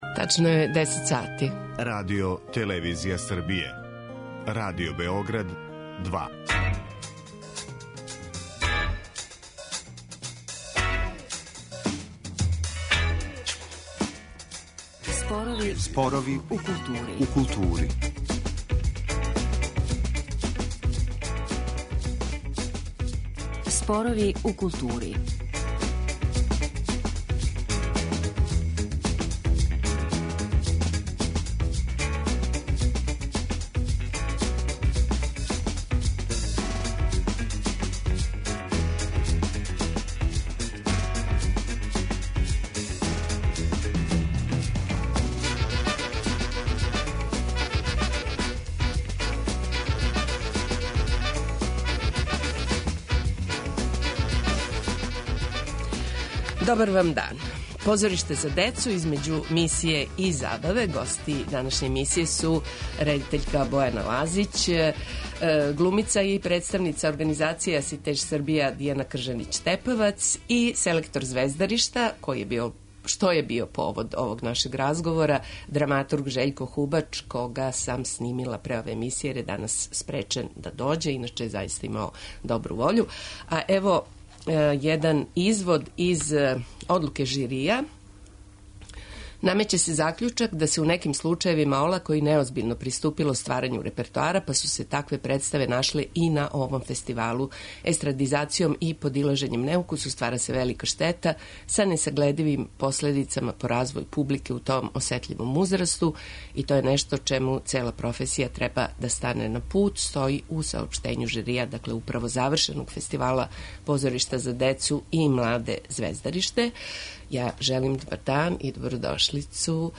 Гости емисије су редитељка